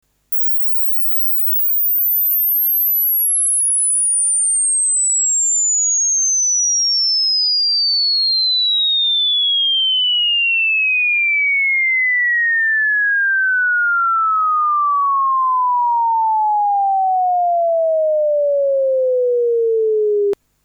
スウィープ信号 -12.0dB (20kHz-400Hz; Sine; Stereo)
ゲインM |